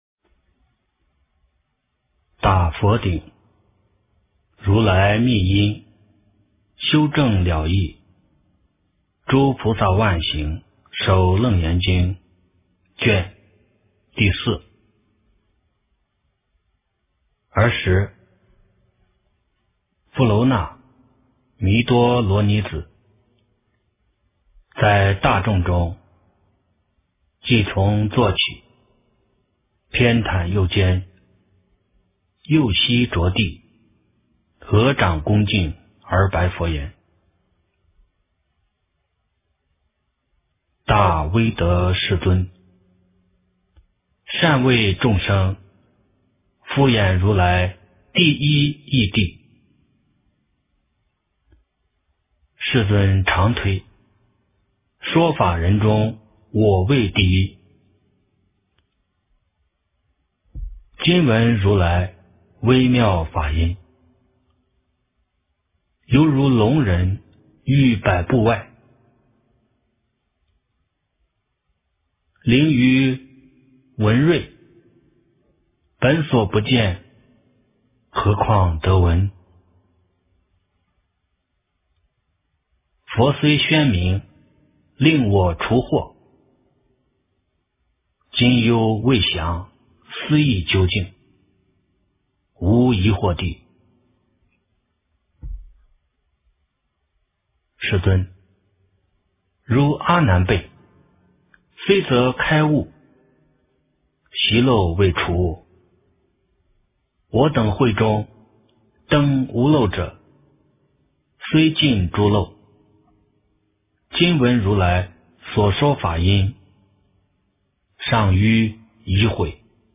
楞严经第04卷(念诵)